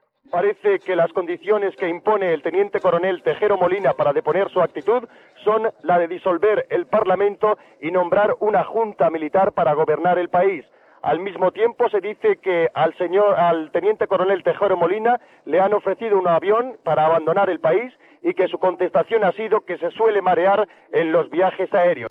A la nit, connexió amb la unitat mòbil de RNE a l'exterior del Congrés de Diputats de Madrid on un grup de guàrdia civils tenen segrestats als diputats en l'intent de fer un cop d'estat.
Informatiu